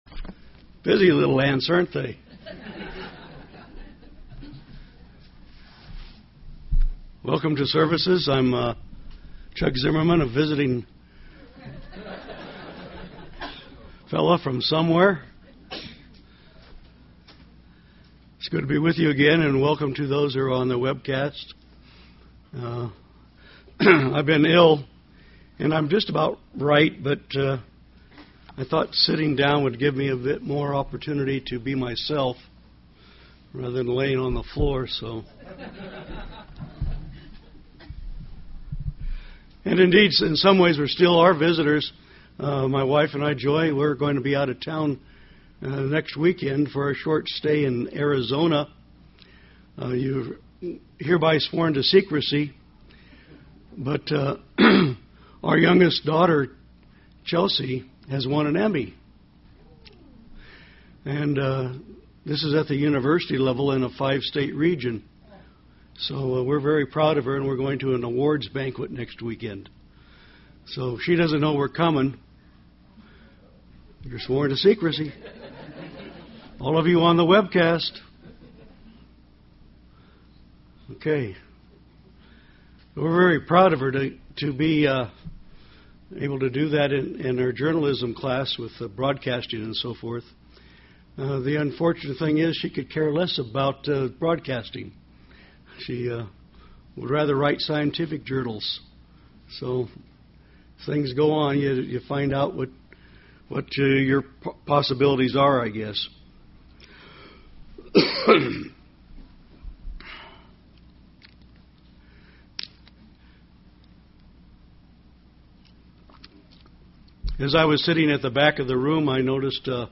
Sermons
Given in Tampa, FL St. Petersburg, FL